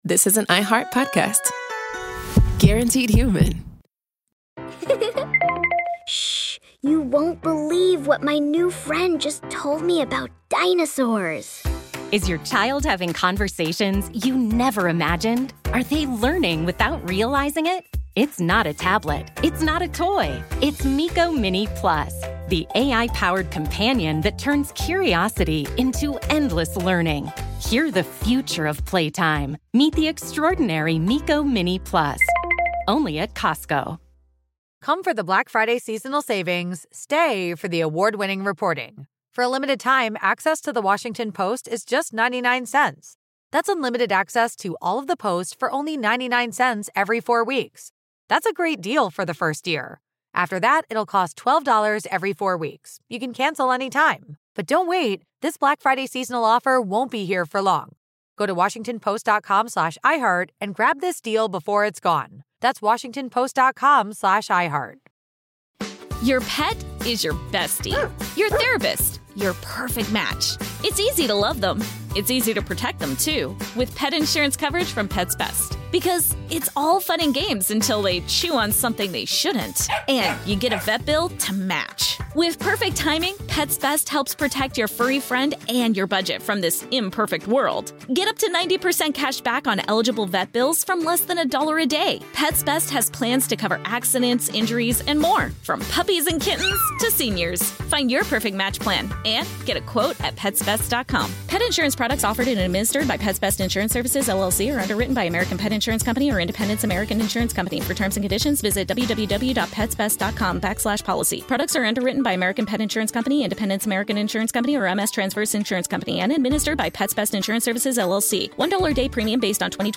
We thank the United States Holocaust Memorial Museum Collection for preserving and sharing this audio.